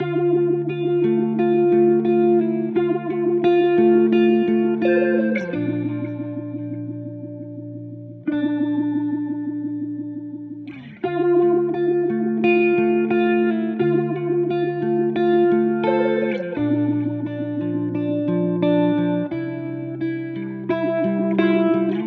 • 18 authentic guitar loops (with Stems: 90 total samples)